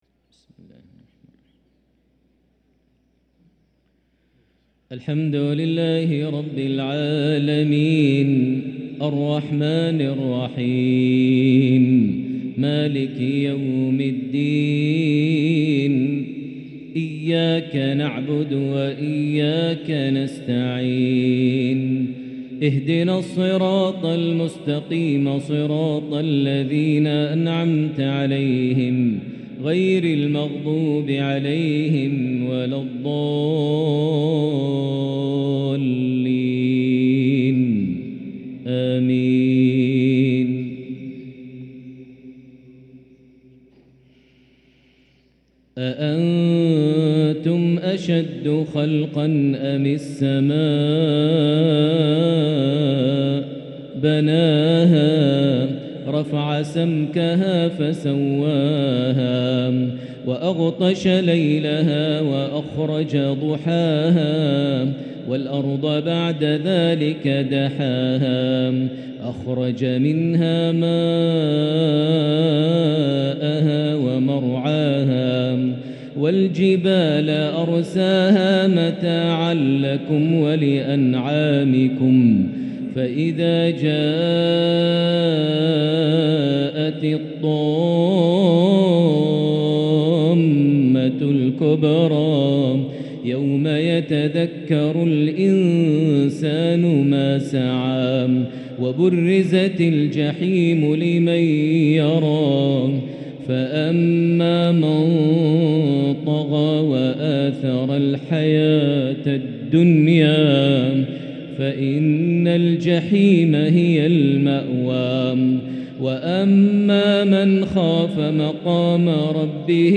تلاوة جميلة لخواتيم سورة النازعات (27-46) مغرب الخميس 3-8-1444هـ > 1444 هـ > الفروض - تلاوات ماهر المعيقلي